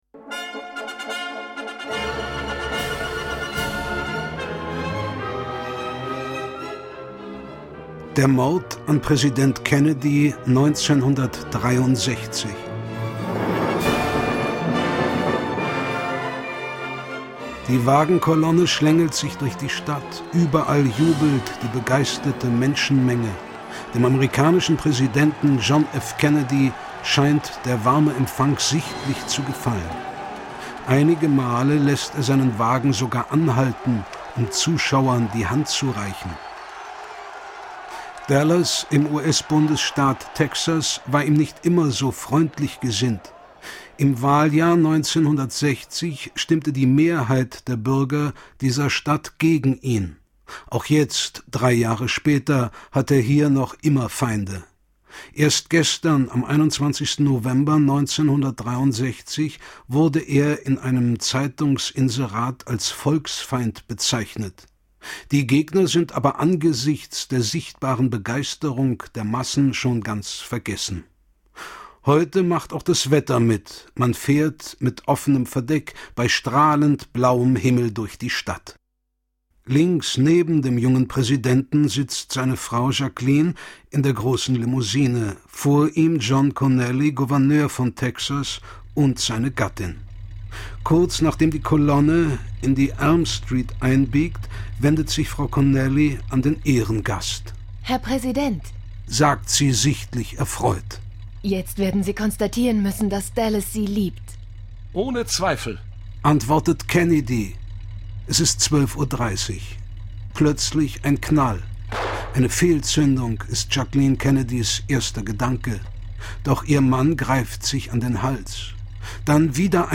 Musik und passende Atmosphäre lassen diese bedeutenden Episoden lebendig werden.